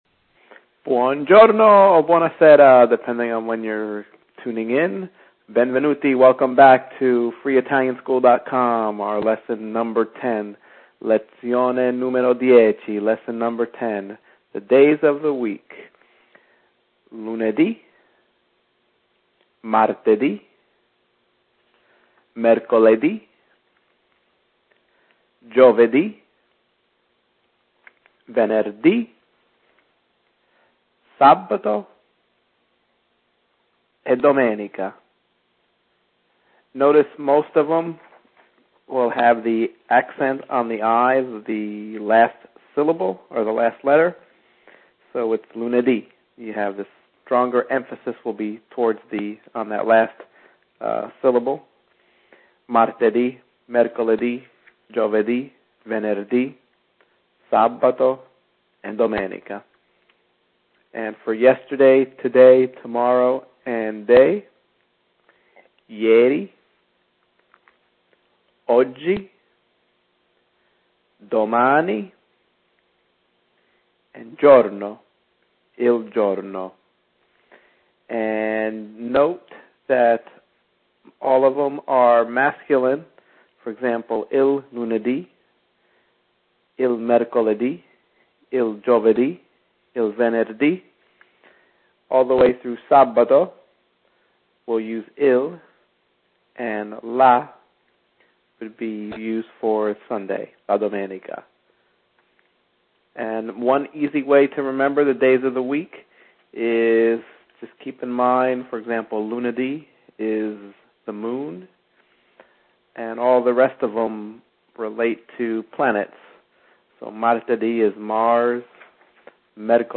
Also notice that the accent on the last letter “i” for the days corresponding from Monday through Friday indicate a strong emphasis on that last syllable.